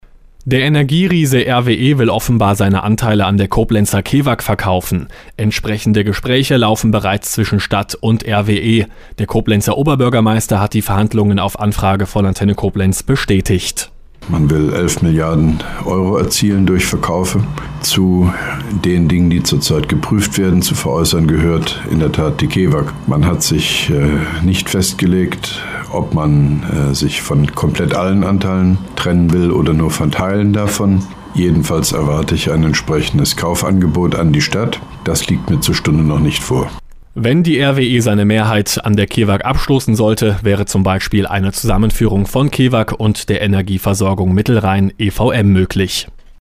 Mit Kurzstellungnahme von OB Hofmann-Göttig
Nachrichten-Antenne-Koblenz-RWEKevag.mp3